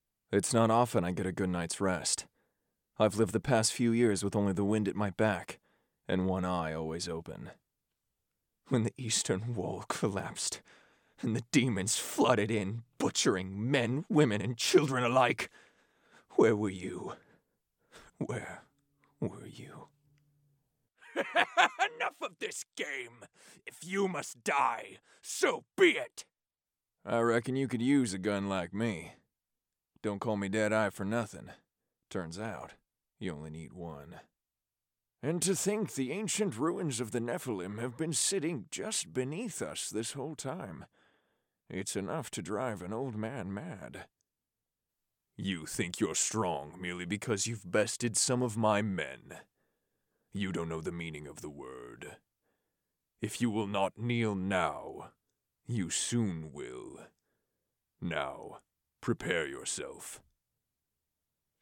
Charismatic male VA with voice smoother than a glass of Pendleton on the rocks.